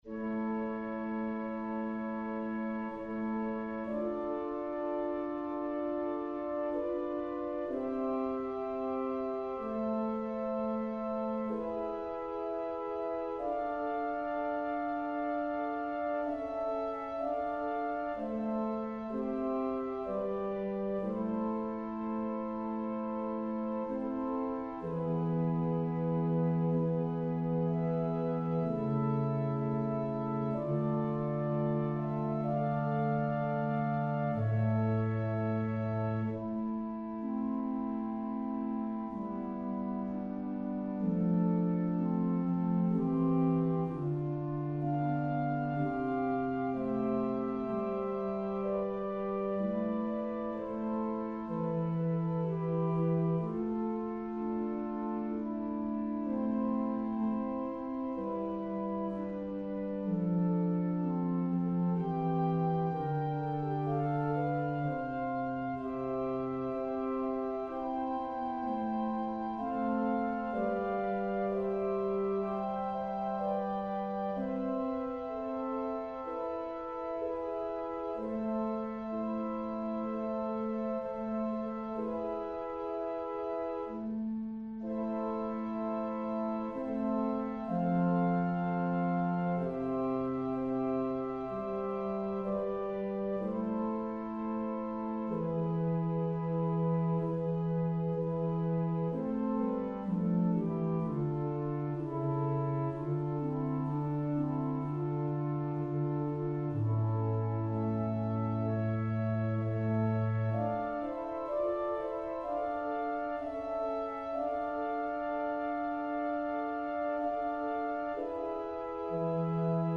For the Third Decade of prayers of The Chaplet of the Divine Mercy, click the ▶ button to listen to an organ setting of a composition of Ave Verum Corpus by the fifteenth-sixteenth century English composer Fr. Peter Philips (1560-1628), or play the music in a New Window